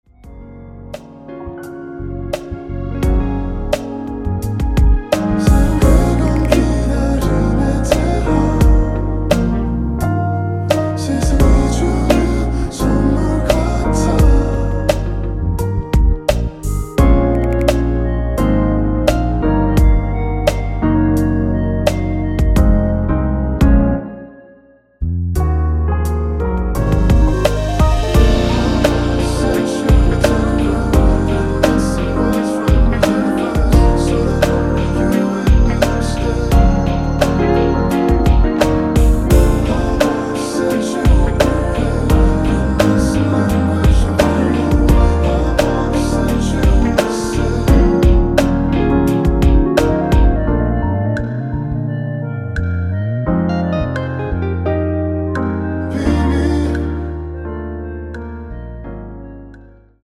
원키에서(-2)내린 멜로디와 코러스 포함된 MR입니다.(미리듣기 확인)
Db
앞부분30초, 뒷부분30초씩 편집해서 올려 드리고 있습니다.
중간에 음이 끈어지고 다시 나오는 이유는